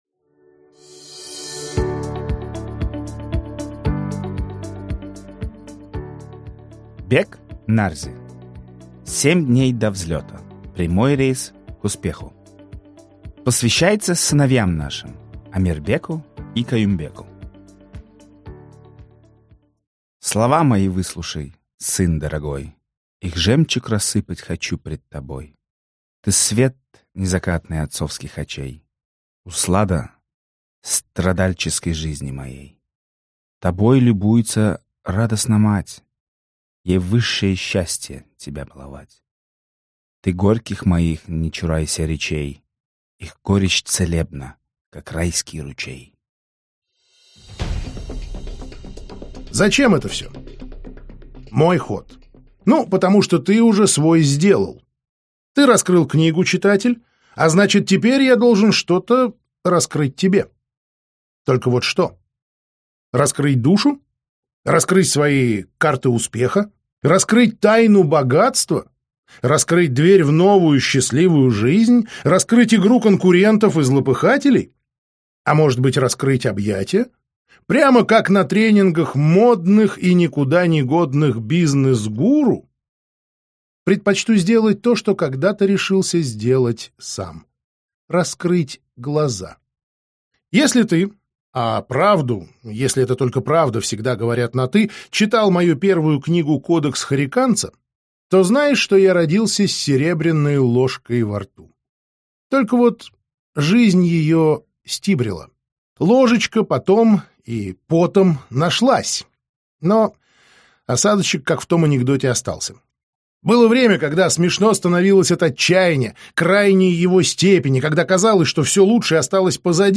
Аудиокнига 7 дней до взлета. Прямой рейс к успеху | Библиотека аудиокниг